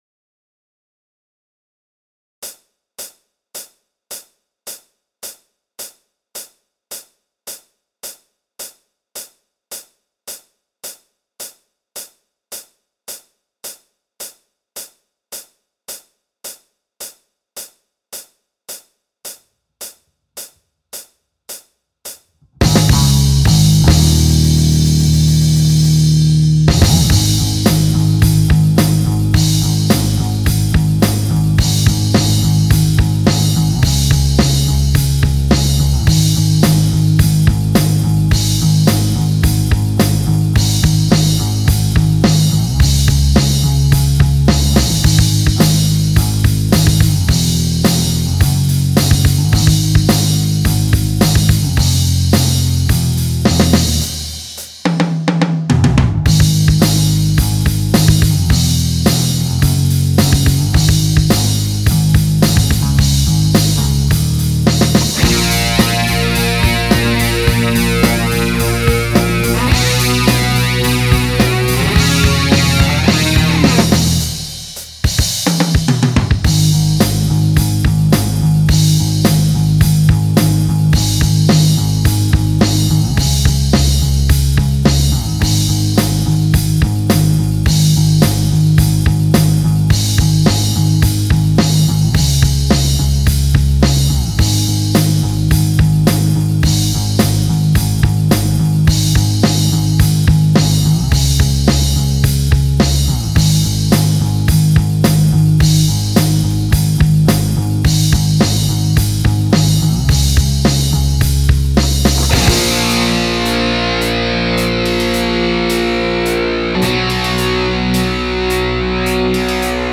You be the main guitar part